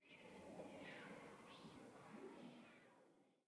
Minecraft Version Minecraft Version snapshot Latest Release | Latest Snapshot snapshot / assets / minecraft / sounds / ambient / nether / soulsand_valley / whisper6.ogg Compare With Compare With Latest Release | Latest Snapshot
whisper6.ogg